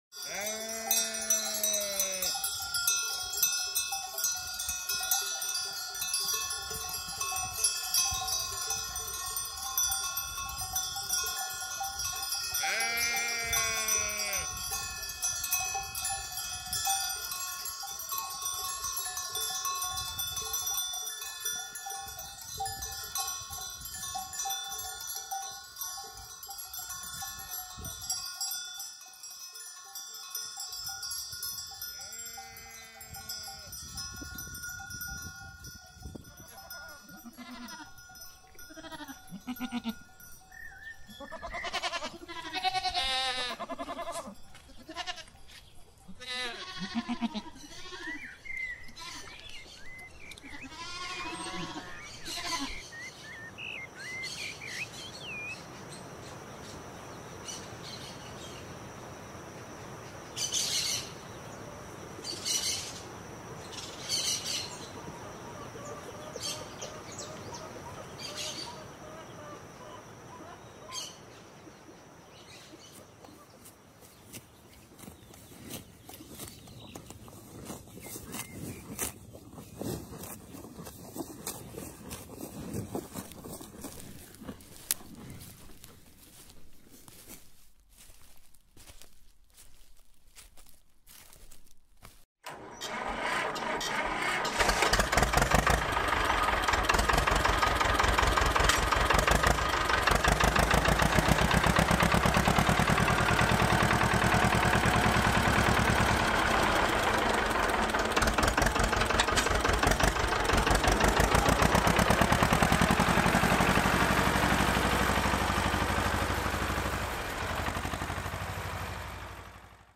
Each element of the artwork captures a part of the farm’s acoustic charm: the distant hum of a tractor, the soft crunch of footsteps in tall grass, the chatter of birds in flight, and the familiar voices of the multiple animals.
campo_mixagem.mp3